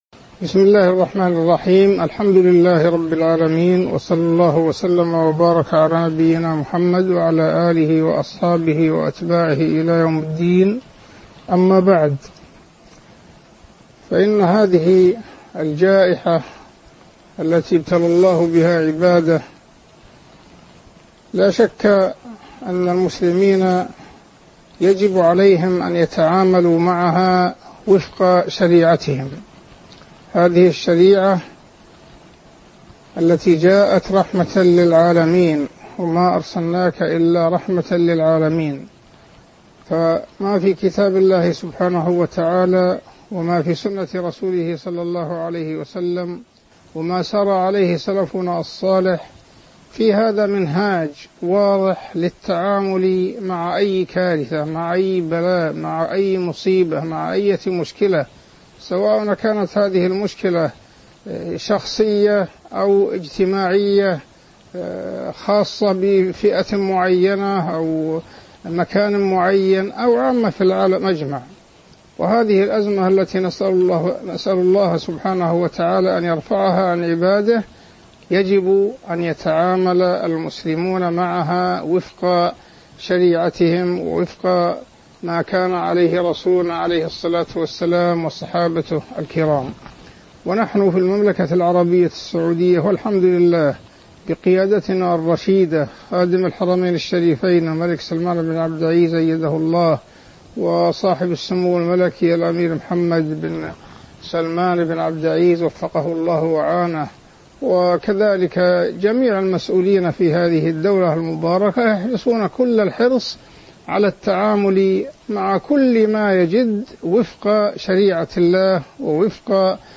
تاريخ النشر ٢ ذو القعدة ١٤٤١ هـ المكان: المسجد النبوي الشيخ: عبدالله التركي عبدالله التركي كيفية التعامل مع ازمة كورونا - الحرمان الشريفان وخصوصيات المملكة (01)